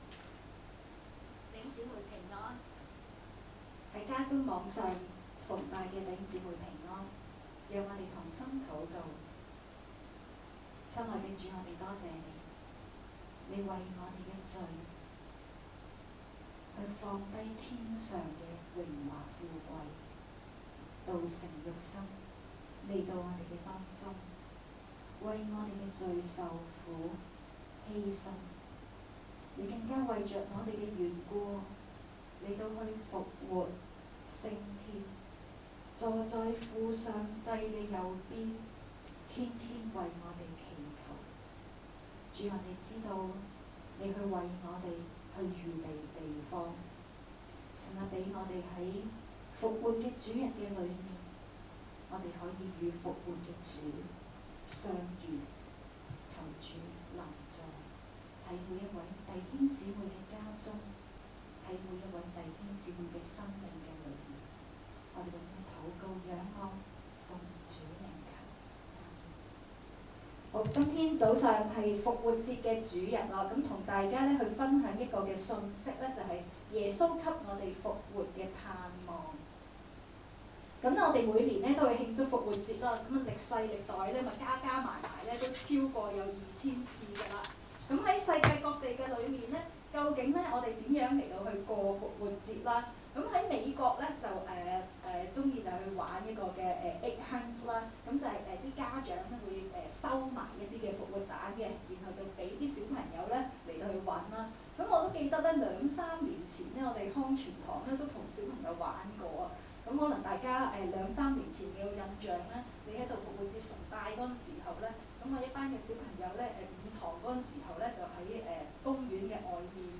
2020年4月12日講道